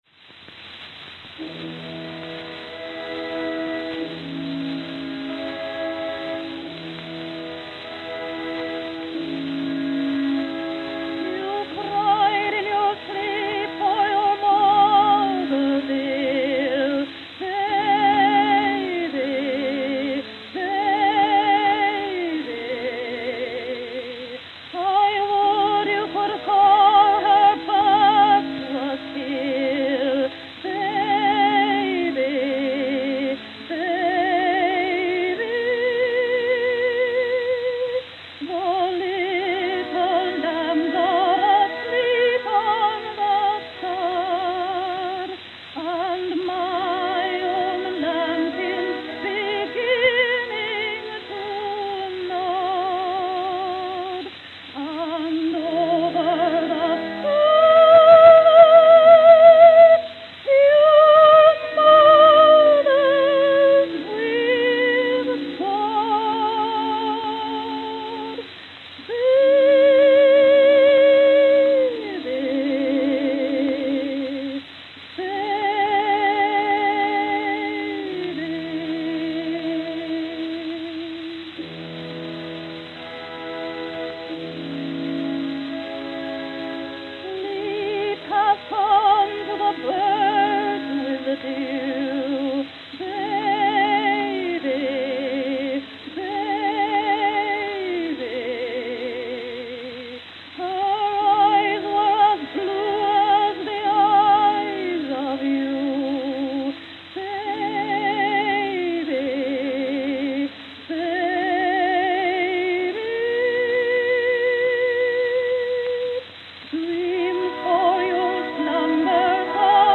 Camden, New Jersey